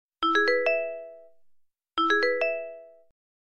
Звук сообщения на Blackberry